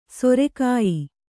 ♪ sore kāyi